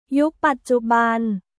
ユック・パッチュバン